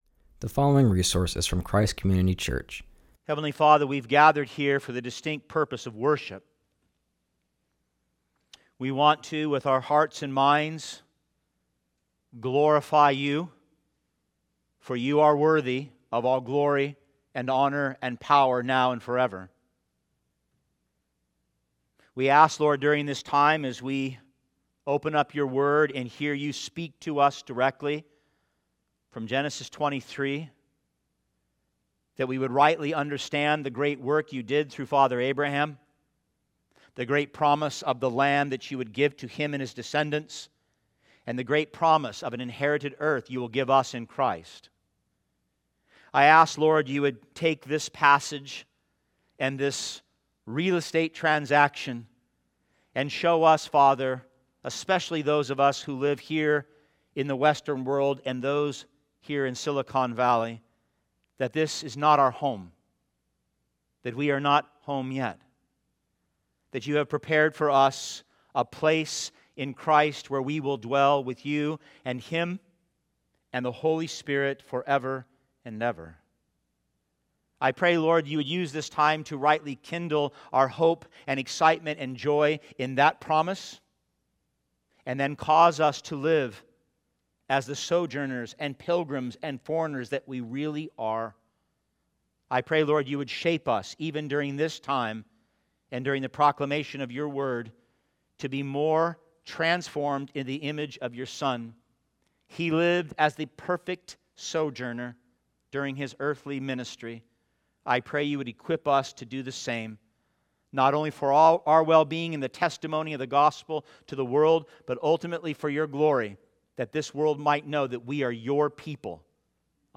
continues our series and preaches from Genesis 23.